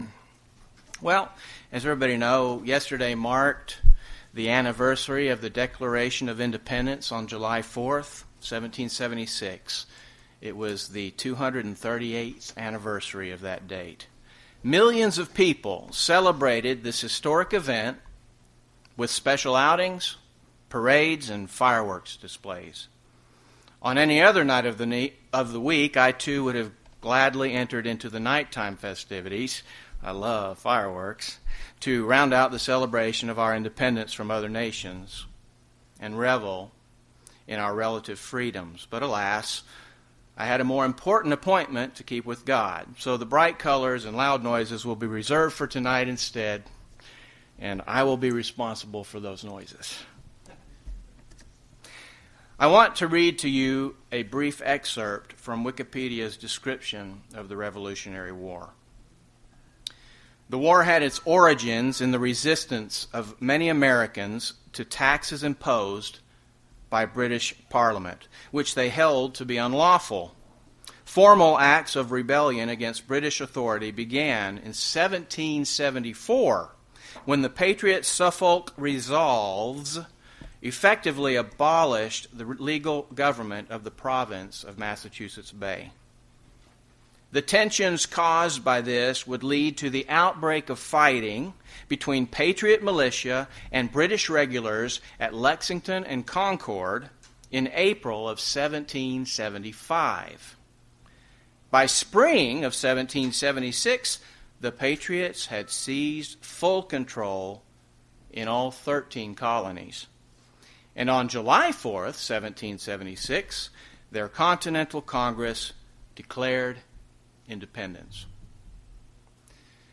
UCG Sermon Declaration of Independence Christian freedom Revolutionary War Notes PRESENTER'S NOTES Yesterday marked the 238th Anniversary of the Declaration of Independence on July 4th, 1776.